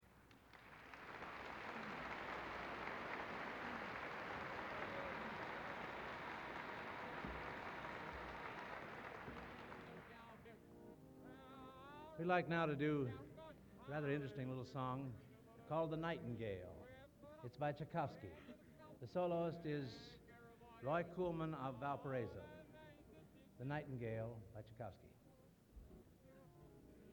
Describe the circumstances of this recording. Collection: Hammond Concert, 1960